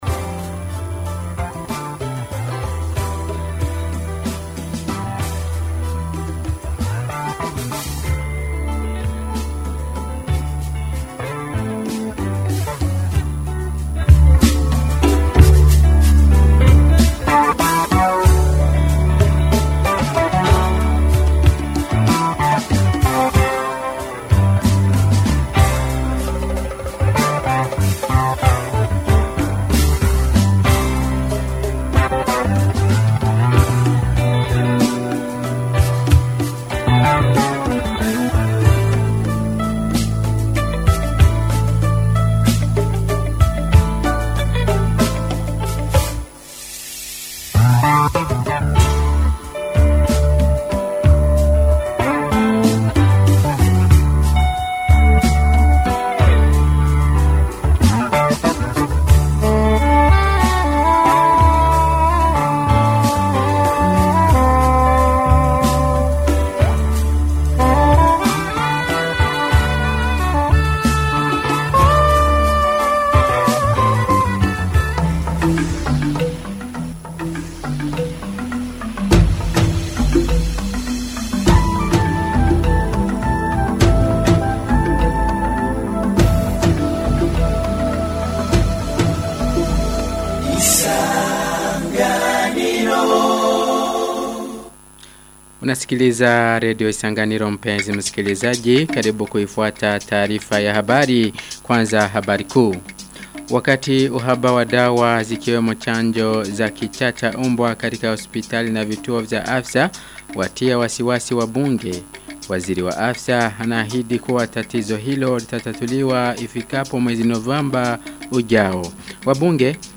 Taarifa ya habari ya tarehe 22 Oktoba 2025